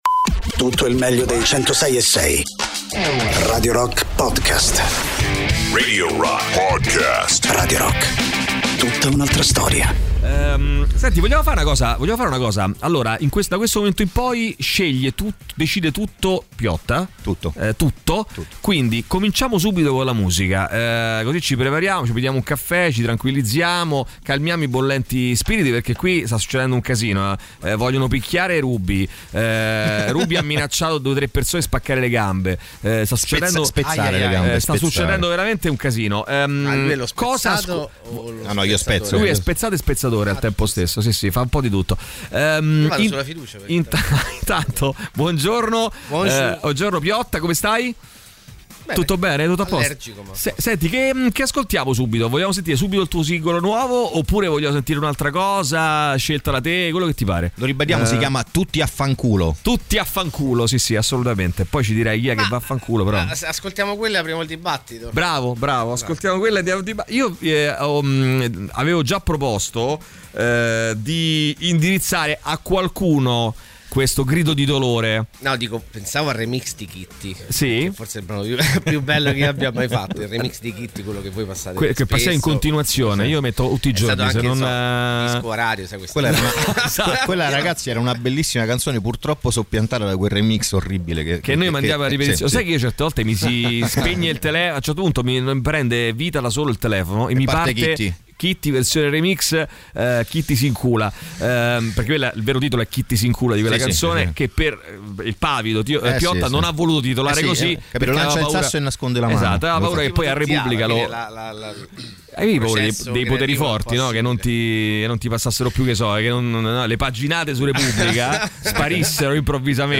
Interviste: Piotta (25-05-23)